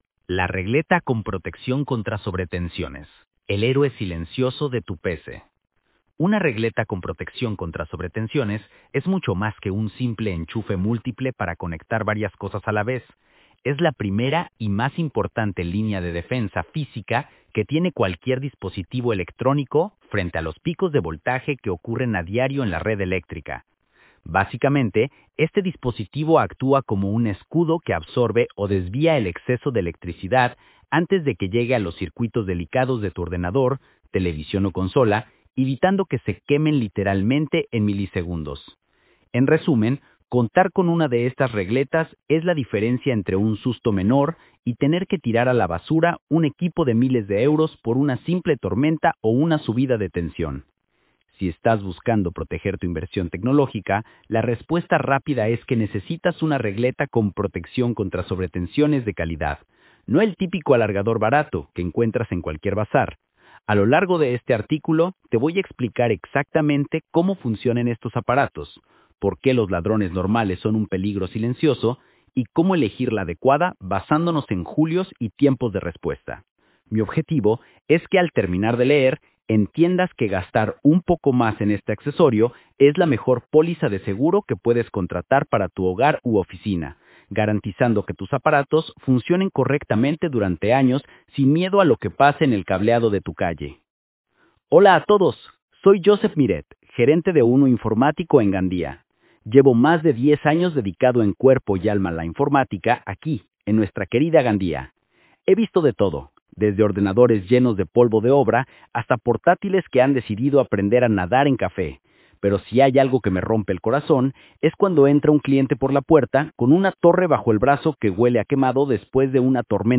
Dale al play para escuchar el artículo Regleta con protección contra sobretensiones